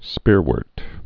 (spîrwûrt, -wôrt)